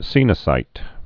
(sēnə-sīt)